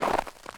pedology_ice_pure_footstep.1.ogg